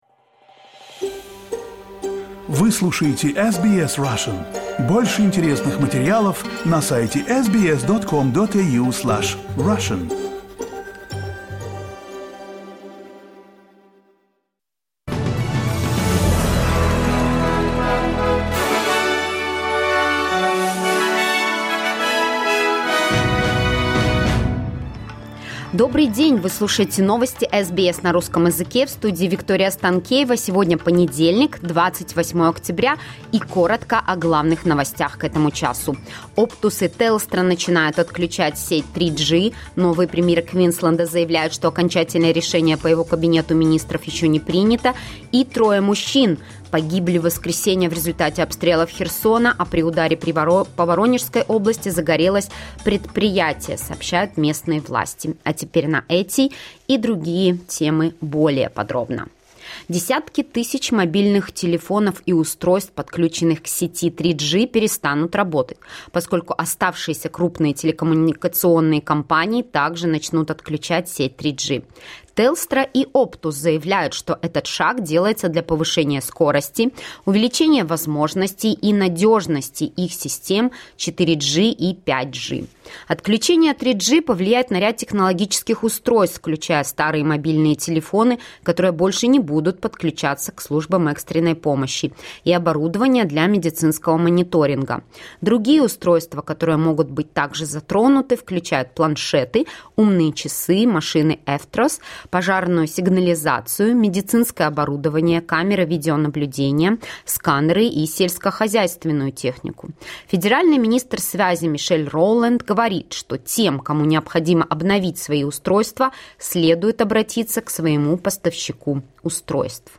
Новости SBS на русском языке — 28.10.2024